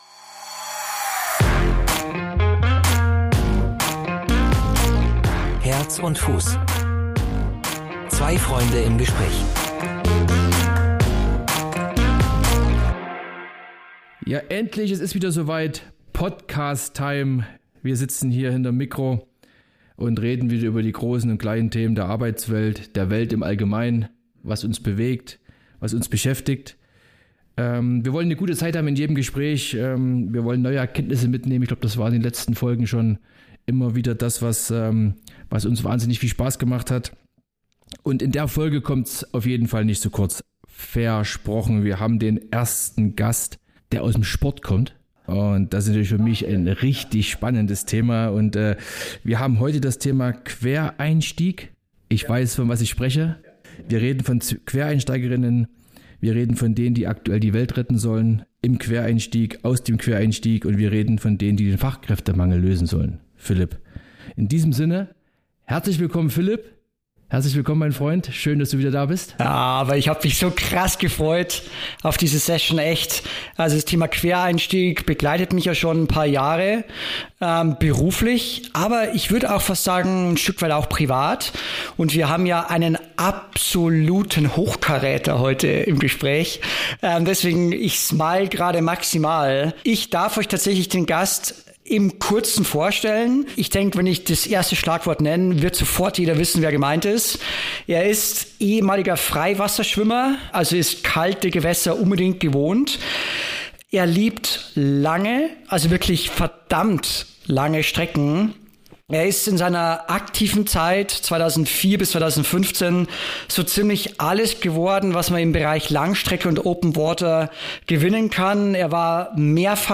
Anregungen und Tipps für modernes Recruiting mit Quereinsteigern gibt Thomas Lurz in diesem Gespräch unter Freunden.